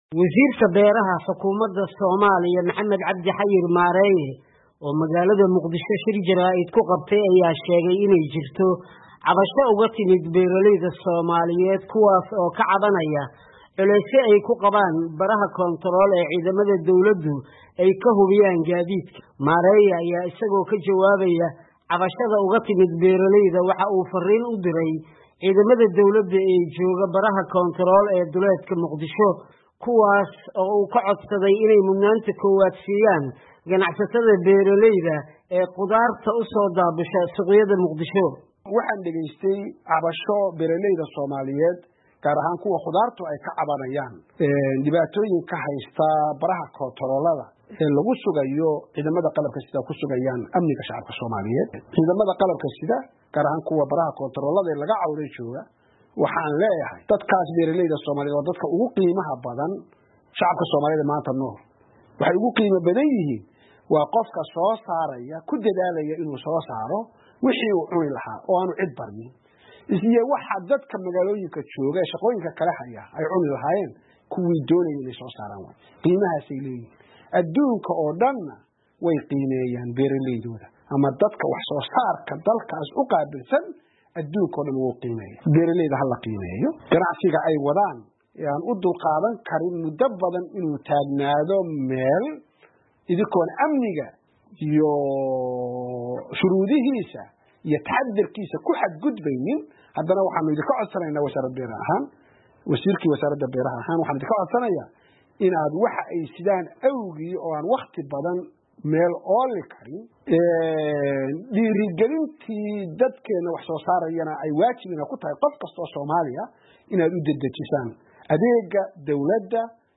Wasiirka beeraha ee dawladda federaalka Soomaaliya Maxamed Cabdi Maareeye ayaa ka hadlay cabashooyin ka yimid beeralayda oo ku saabsan baraha amniga ee yaalla wadodoyinka ay soo maraan gaadiidka sida wax soo saarka beeraha.